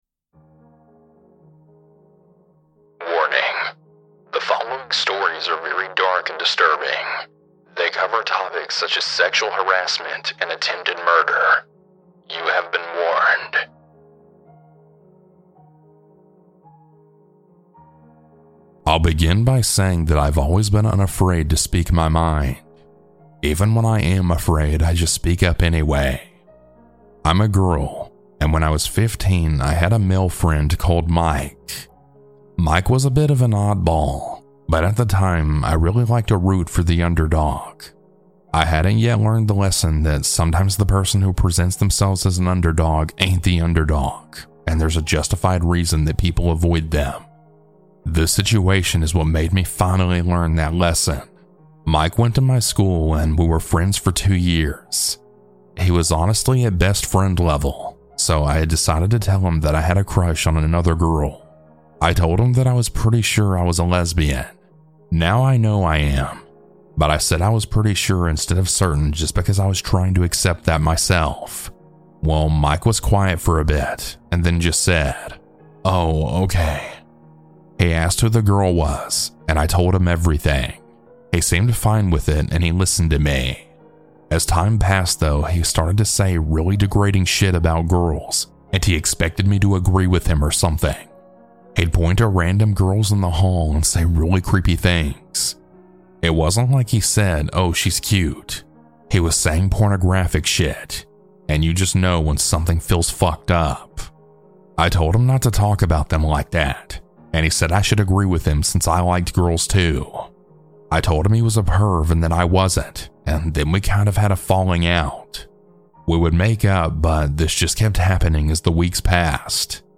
- Anonymous Huge Thanks to these talented folks for their creepy music!